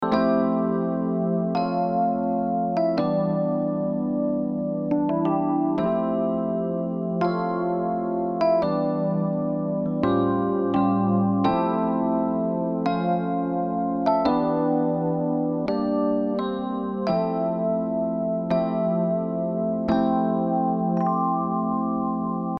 アタック遅め